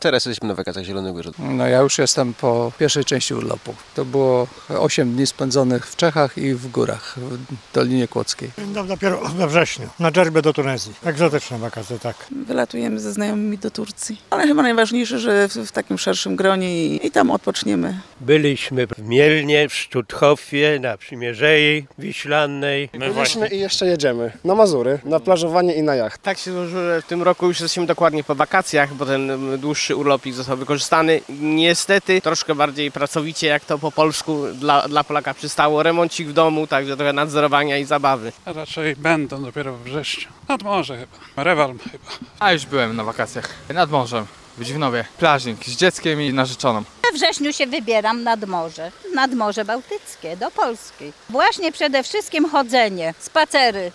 Półmetek wakacji 2019 [SONDA]
Zapytaliśmy mieszkańców czy wakacje już za nimi?: